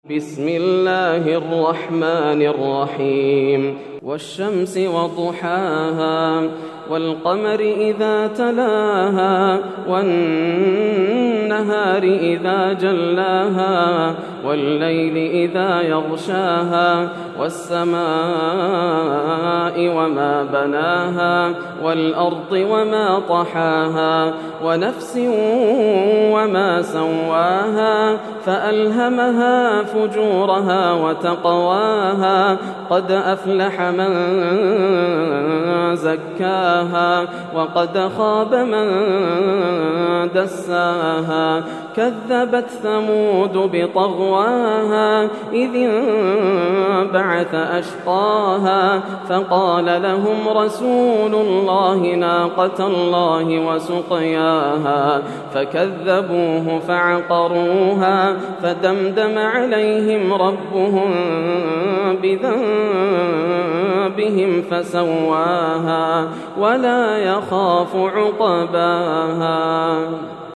سورة الشمس > السور المكتملة > رمضان 1431هـ > التراويح - تلاوات ياسر الدوسري